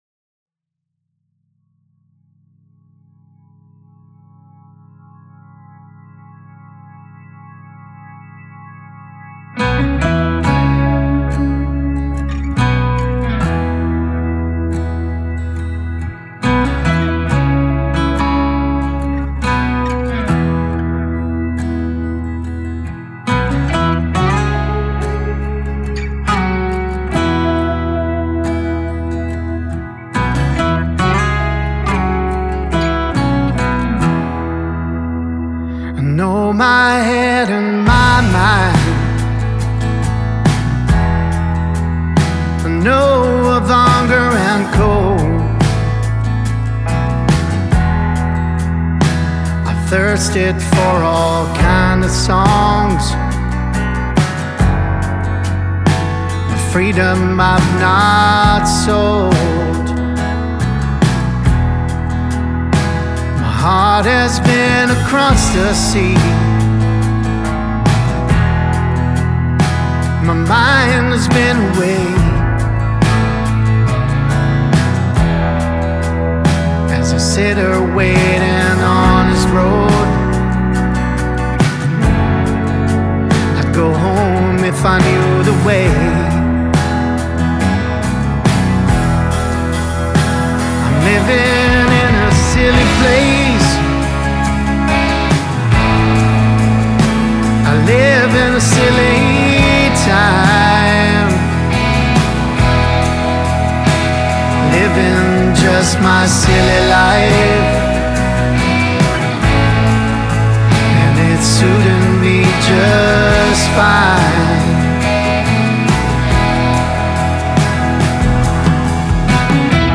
Genere: Pop.